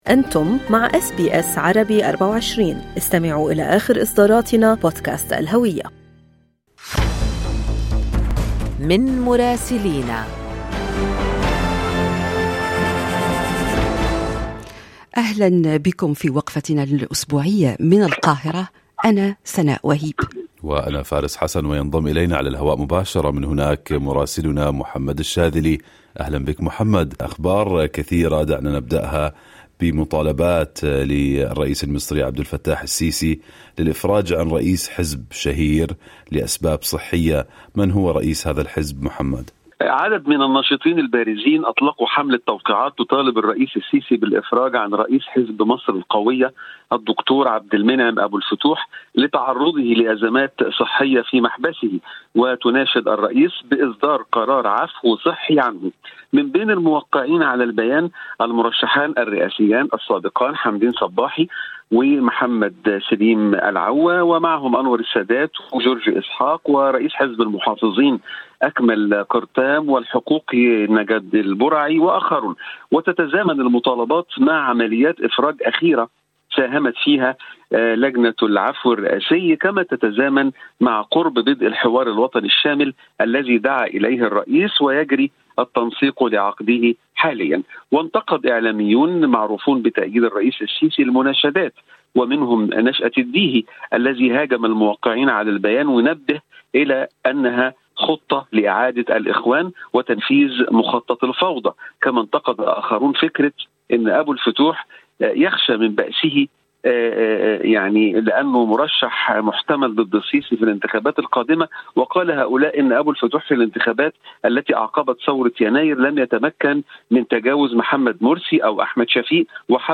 يمكنكم الاستماع إلى تقرير مراسلنا في القاهرة بالضغط على التسجيل الصوتي أعلاه.